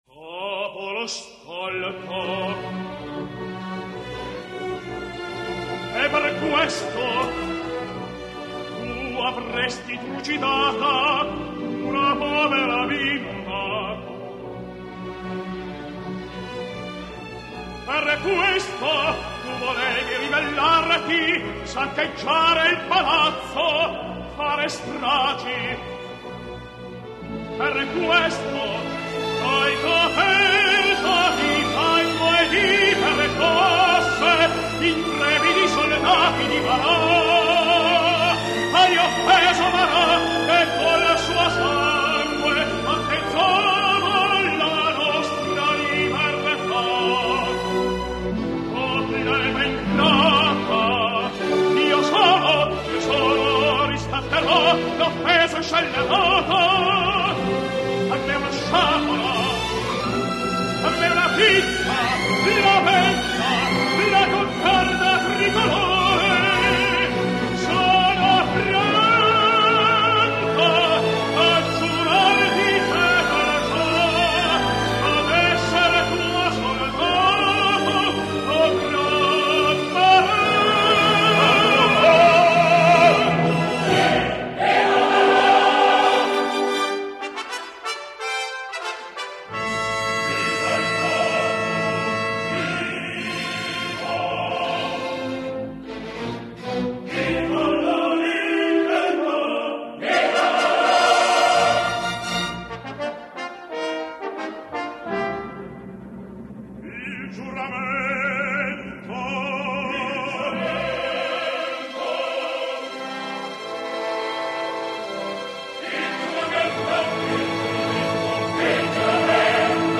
Revolutionsoper — {historischer Verismo}